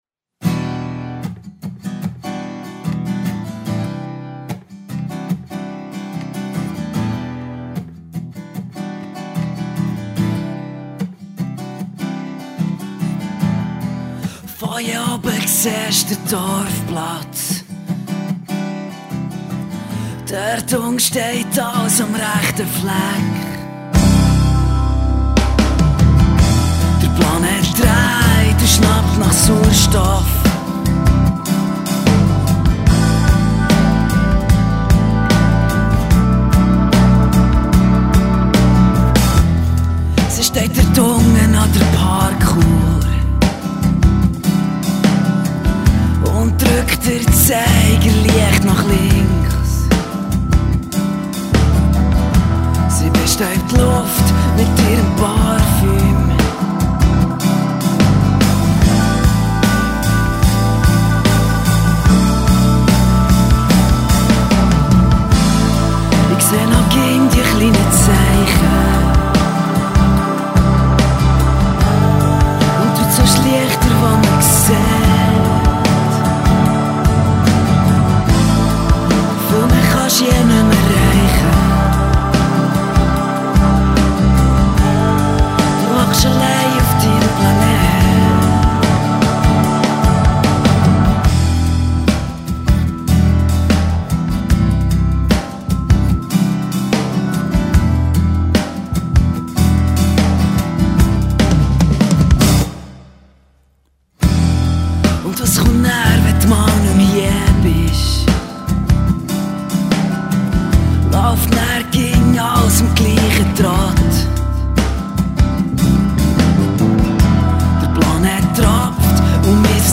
Pop / Rock / Swiss-German Dialect.